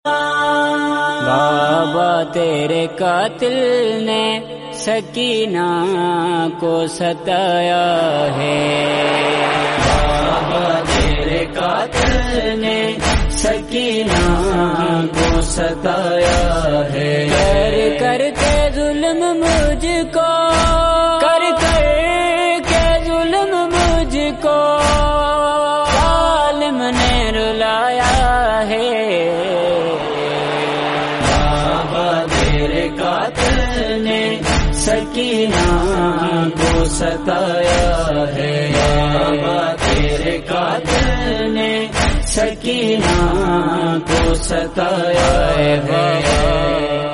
Noha